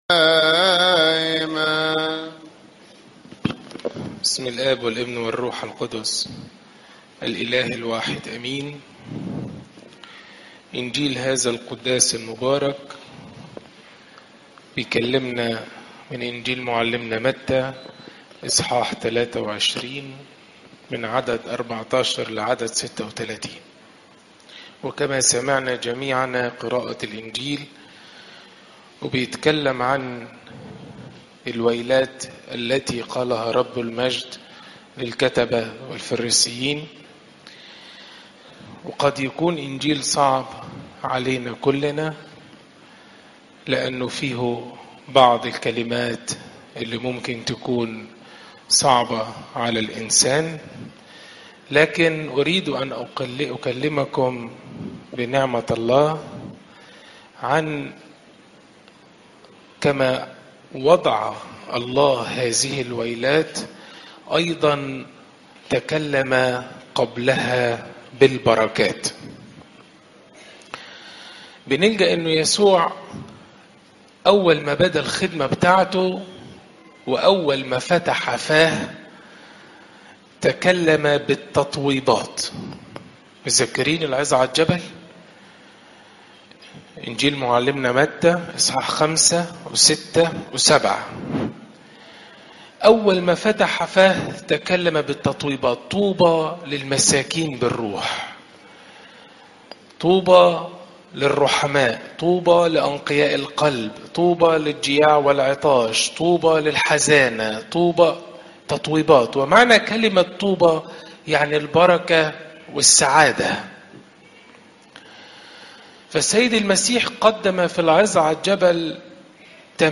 عظات قداسات الكنيسة (مت 23 : 14 - 36) صوم الرسل